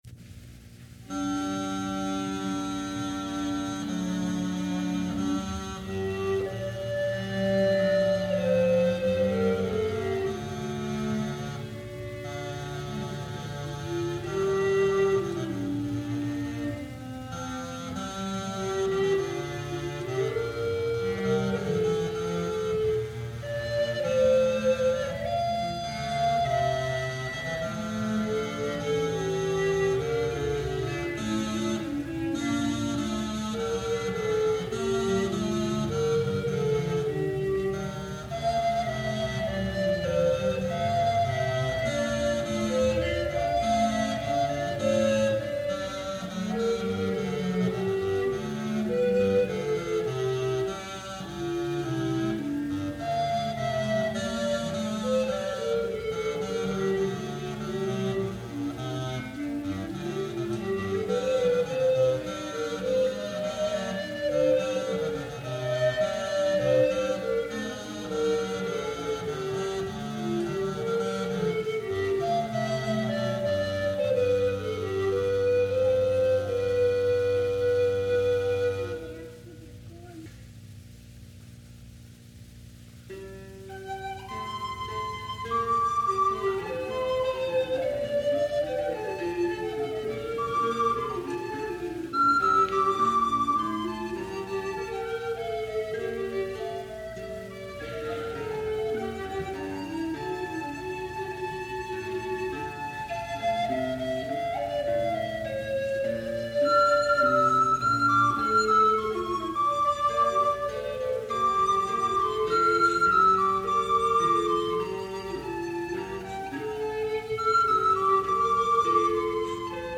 | Instrumental Ensemble, 'Apollo' 1978
The 3 sections are assigned different instrumentations in this arrangement.